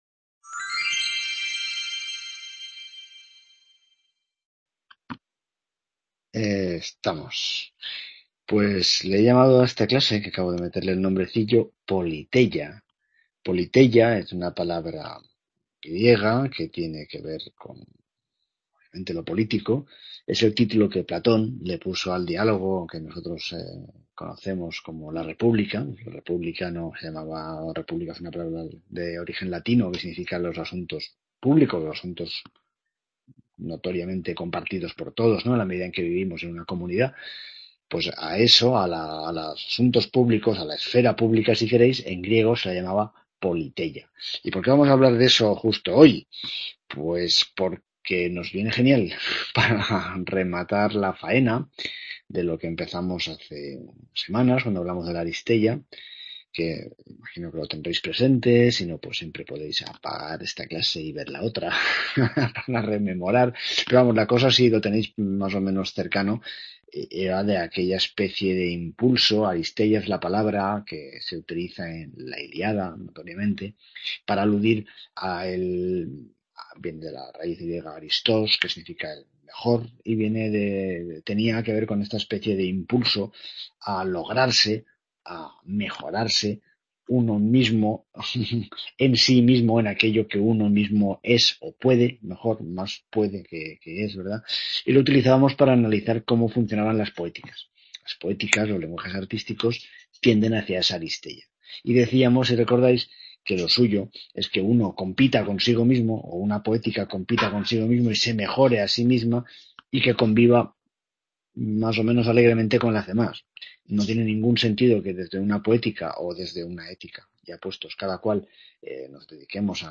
Clase que complementa la anterior y que vivedios que tiene su aquel!